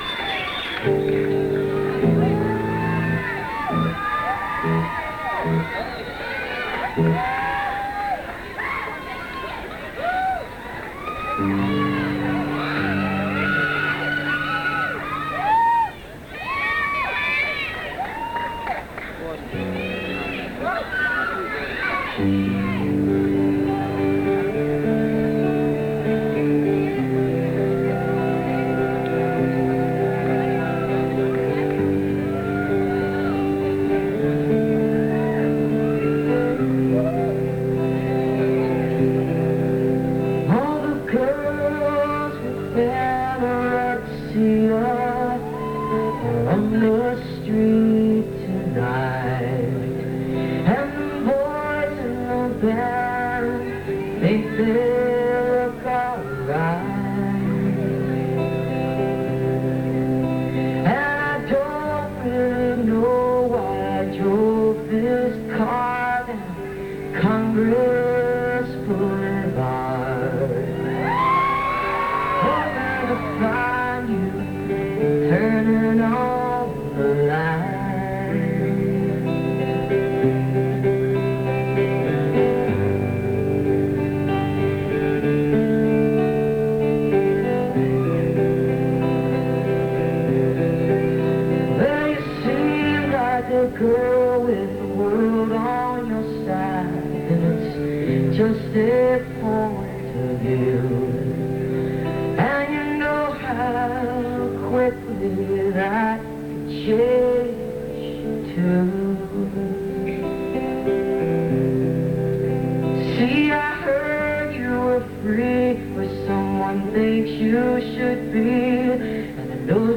(incomplete, band show)